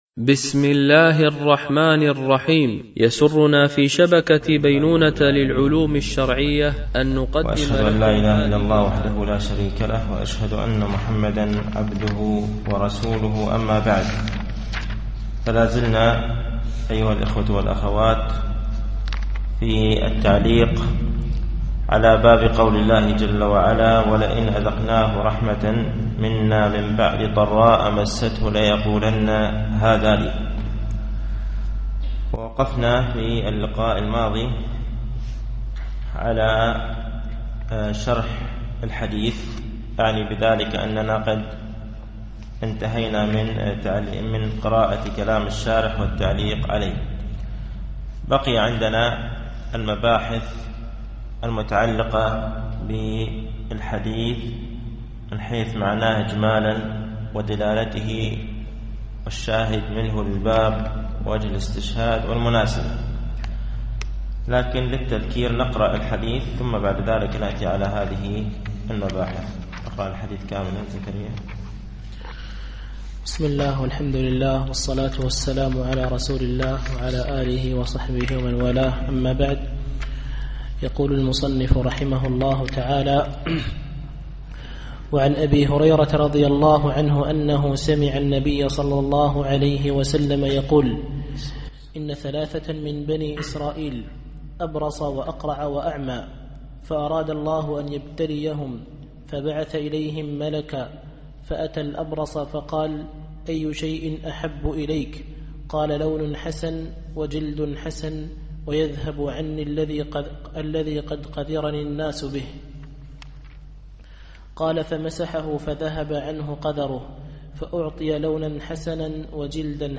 التعليق على القول المفيد على كتاب التوحيد ـ الدرس التاسع و الثلاثون بعد المئة